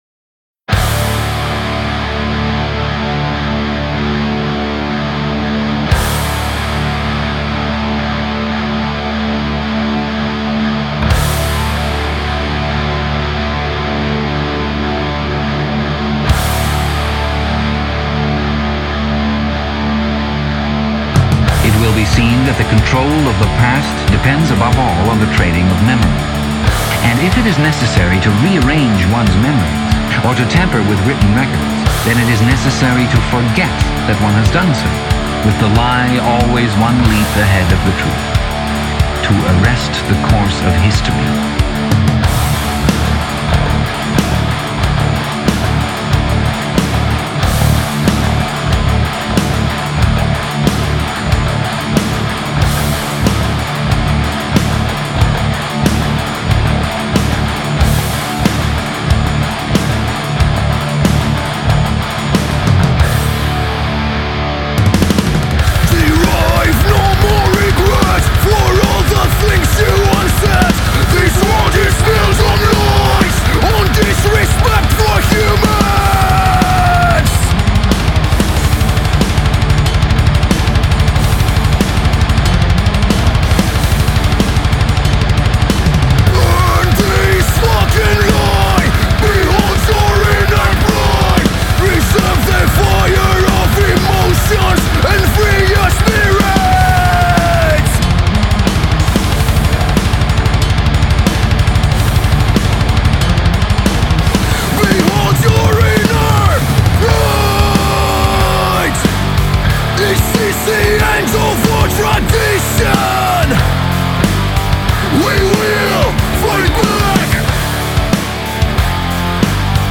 Viertes Album der griechischen Metalband.